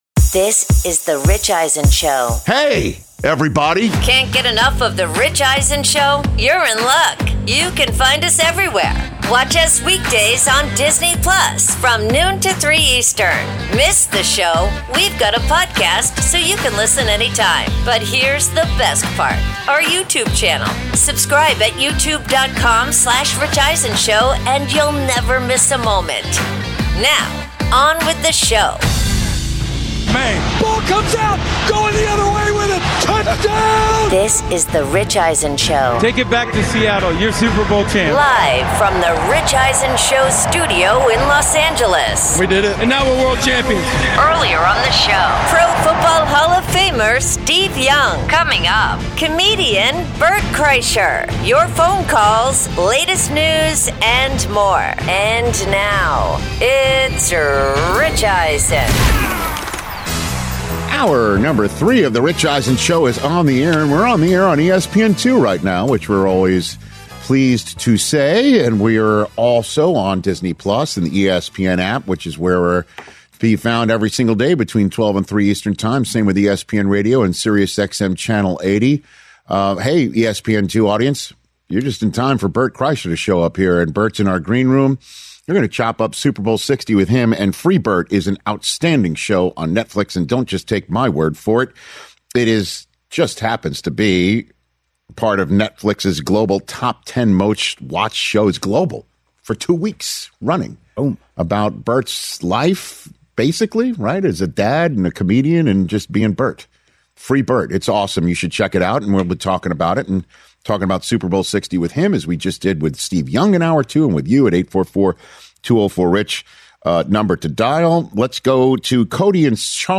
Hour 3: Bert Kreischer In-Studio, Bad Bunny’s Halftime Show, plus Super Bowl 61 Logo Conspiracy Theories